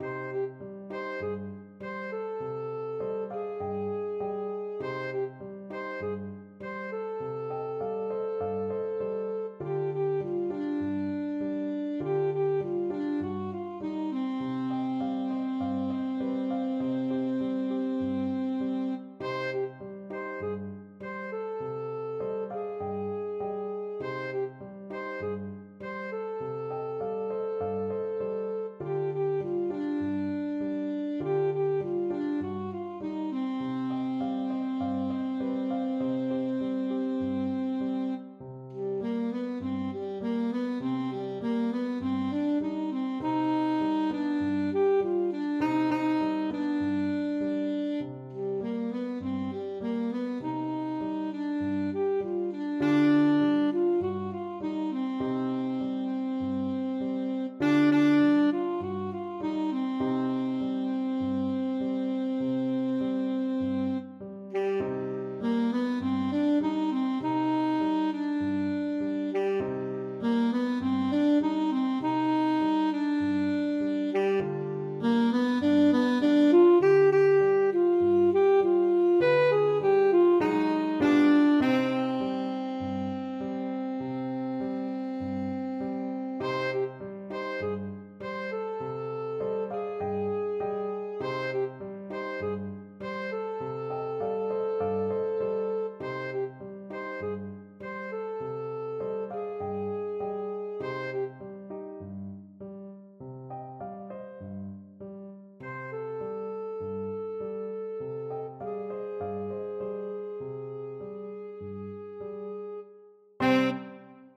Free Sheet music for Alto Saxophone
Alto SaxophoneAlto Saxophone
4/4 (View more 4/4 Music)
Animato